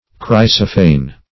chrysophane - definition of chrysophane - synonyms, pronunciation, spelling from Free Dictionary
Search Result for " chrysophane" : The Collaborative International Dictionary of English v.0.48: Chrysophane \Chrys"o*phane\, n. [Gr. chryso`s gold + ? to show.]